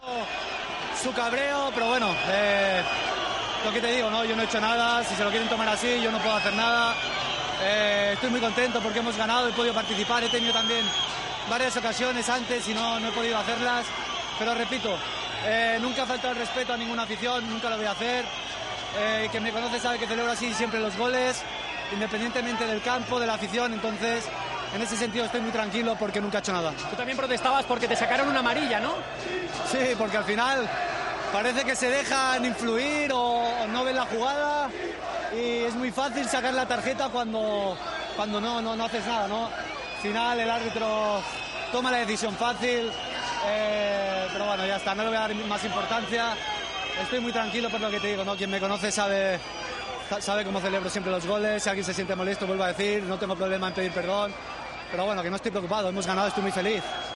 El jugador del Villarreal ha hablado tras el partido en DAZN contra los rojiblancos sobre la polémica formada tras su gol: "Entiendo el cabreo, pero yo no he hecho nada".